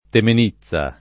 Temenizza [ temen &ZZ a ]